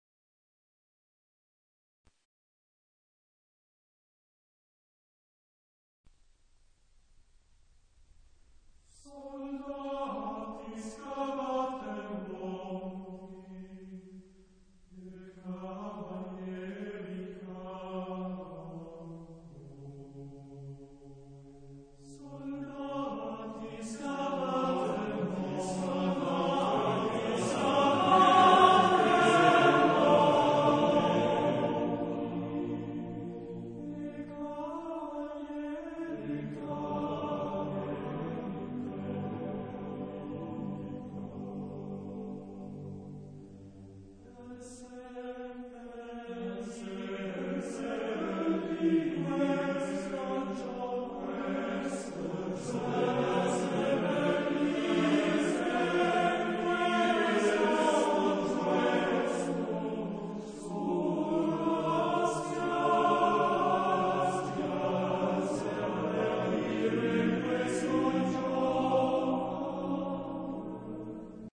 Genre-Style-Forme : Profane ; Lied
Type de choeur : TTBB  (4 voix égales d'hommes )